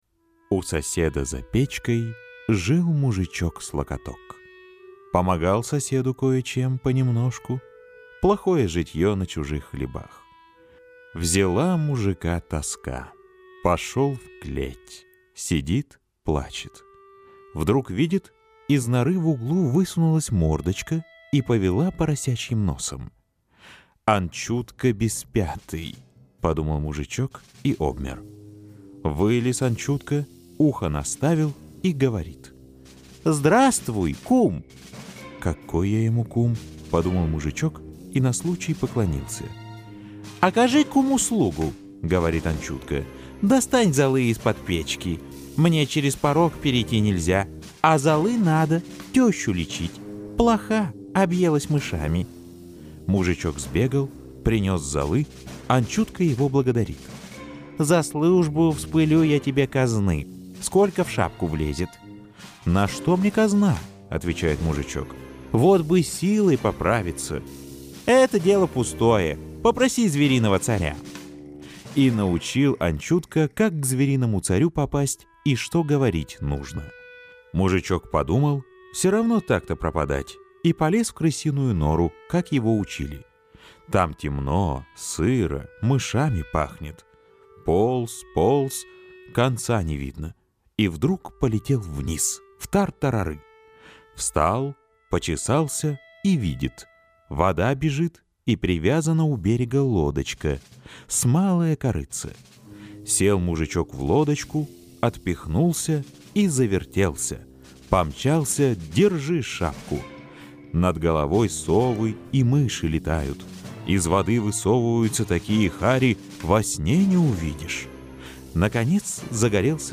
Звериный царь – Толстой А.Н. (аудиоверсия)